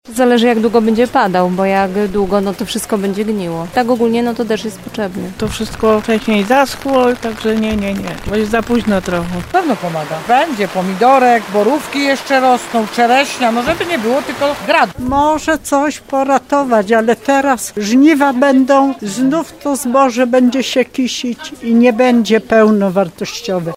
Po wielu tygodniach suszy to kropla w morzu potrzeb – mówią rolnicy z regionu tarnowskiego. Bez systematycznych opadów w regionie plony w tym roku będą znacznie niższe.